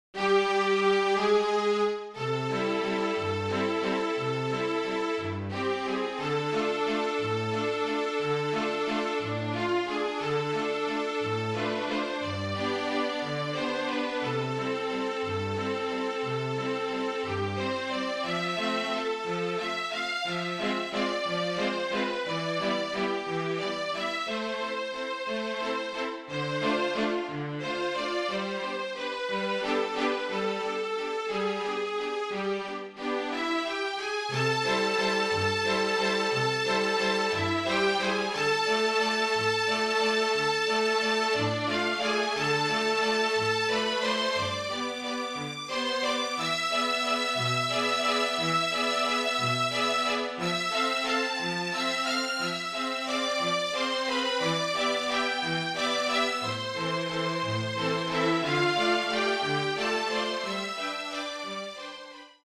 (Flute, Violin, Viola and Cello)
MIDI